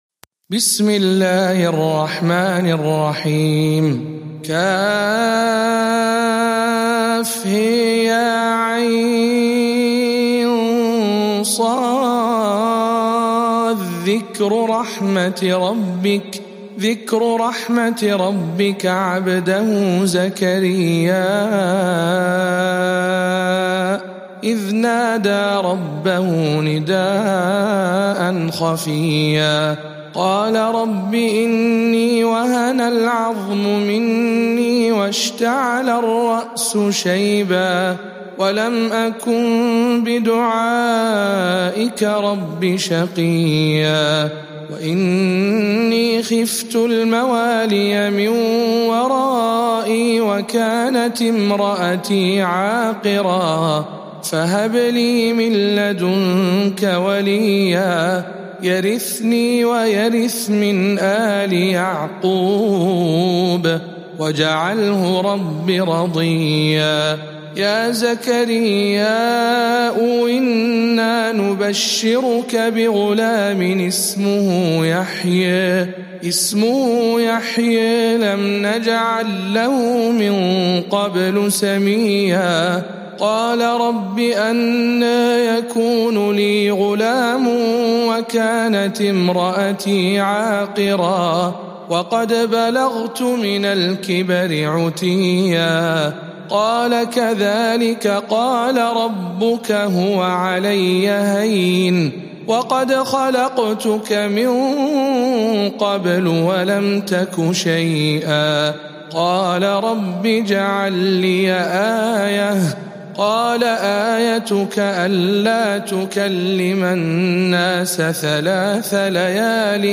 سورة مريم برواية الدوري عن أبي عمرو